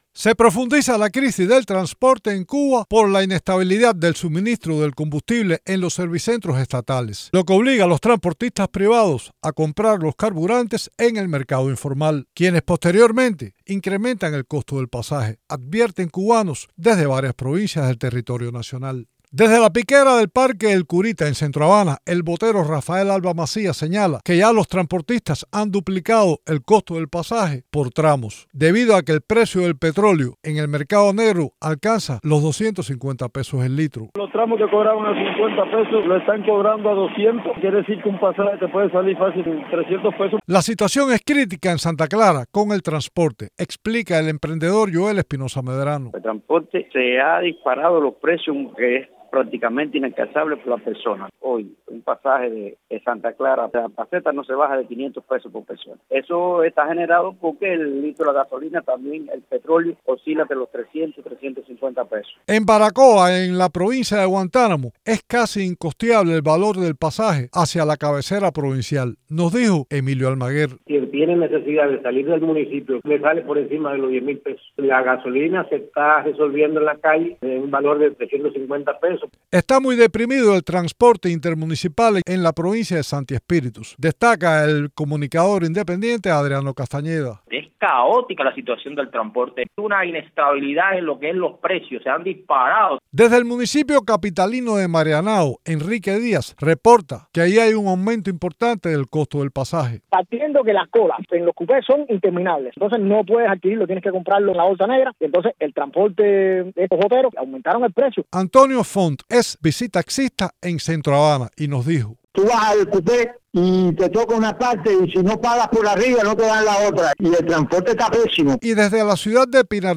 Testimonios de residentes en varias provincias cubanas sobre la crisis del transporte